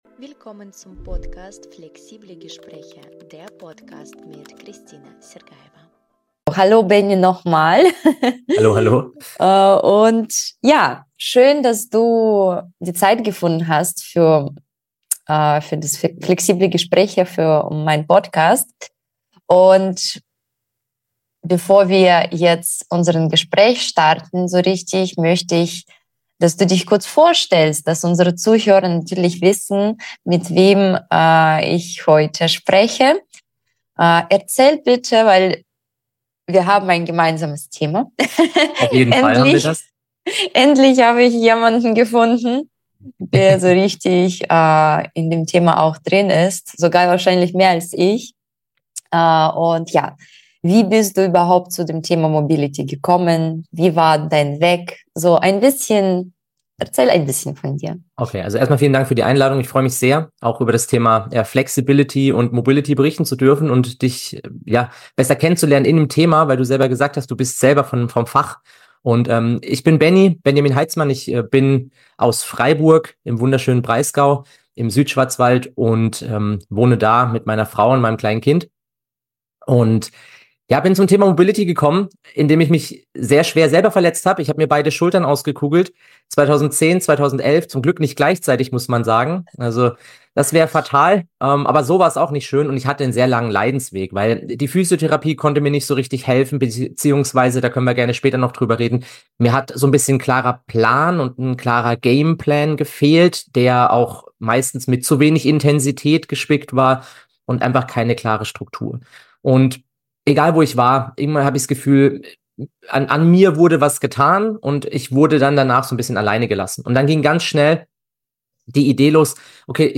Ein ehrliches, tiefgehendes Gespräch über Bewegung, Gesundheit und warum dranbleiben alles verändern kann. Dies ist eine Folge des Podcasts „Flexible Gespräche“